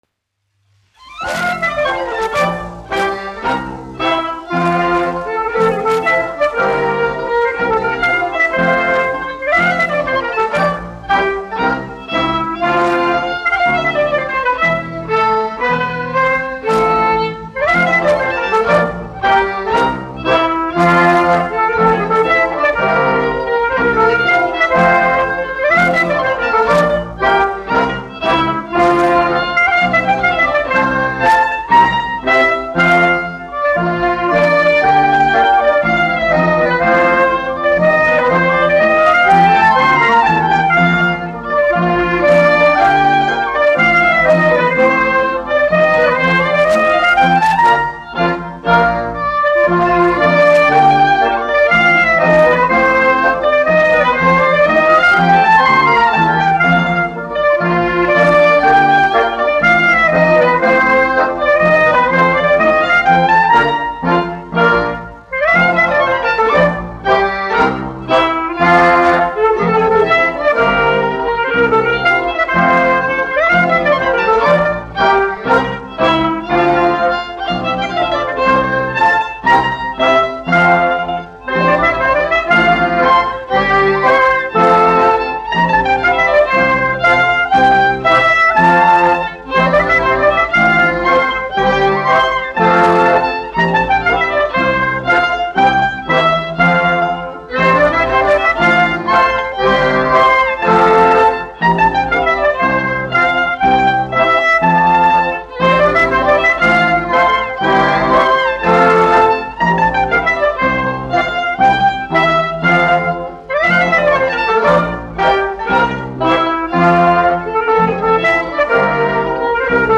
1 skpl. : analogs, 78 apgr/min, mono ; 25 cm
Tautas dejas
Latvijas vēsturiskie šellaka skaņuplašu ieraksti (Kolekcija)